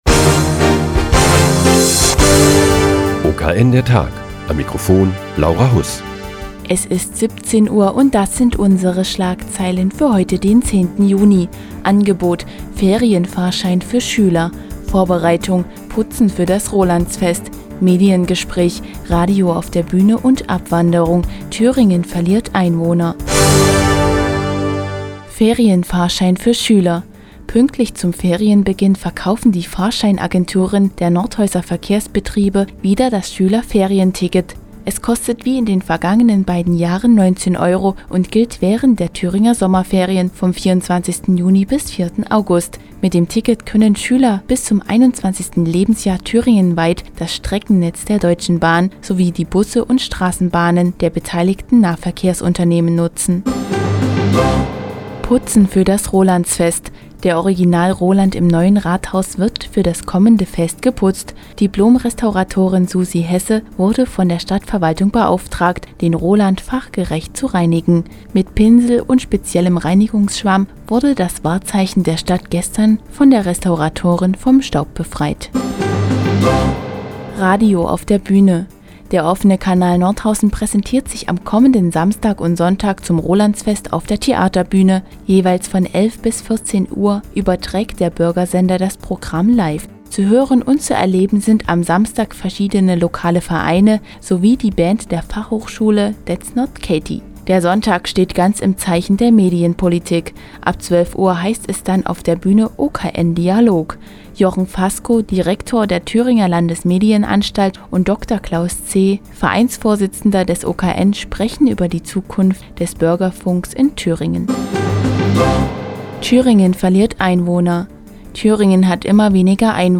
Die tägliche Nachrichtensendung des OKN ist nun auch in der nnz zu hören. Heute geht es um das Schüler- Ferien- Ticket und die Einwohnerabwanderung in Thüringen.